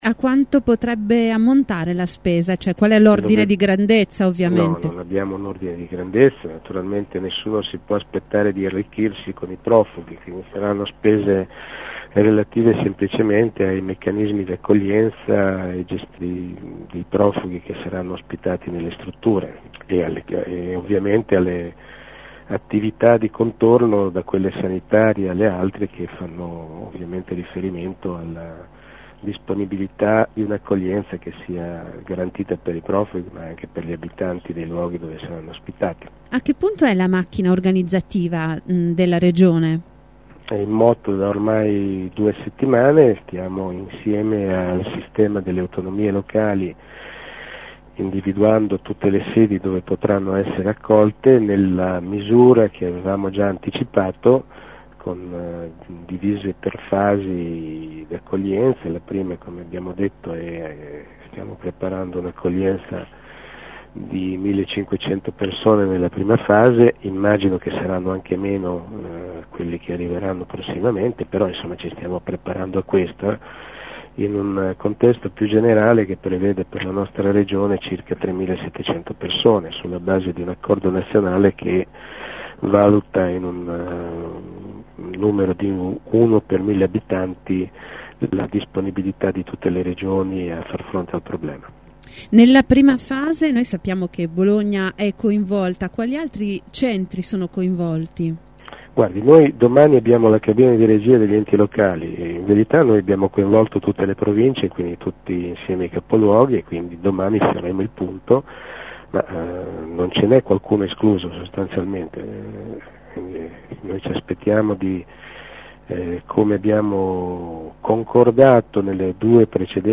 Domani in Regione si terrà la seconda cabina di regia che coinvolgerà province e comuni. Il sottosegretario alla presidenza della Giunta Alfredo Bertelli ai nostri microfoni ha rassicurato i sindaci dicendo che i finanziamenti necessari all’accoglienza arriveranno dal Governo.